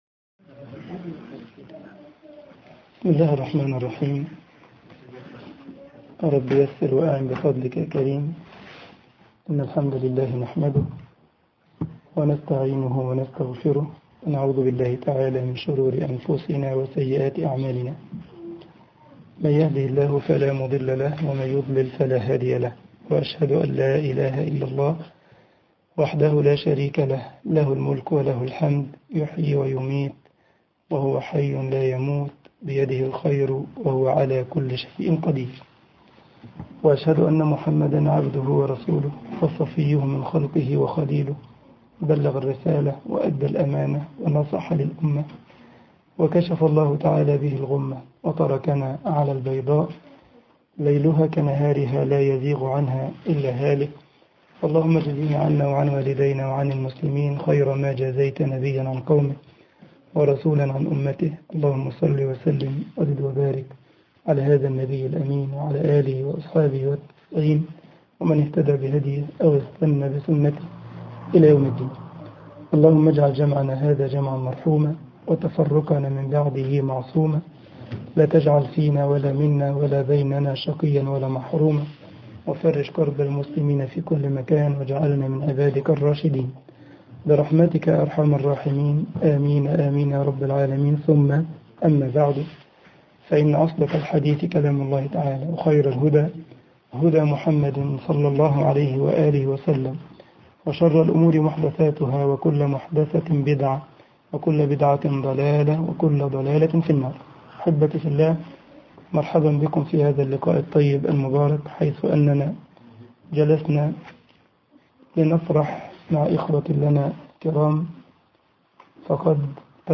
درس
جمعية الشباب المسلمين بزلتسباخ - ألمانيا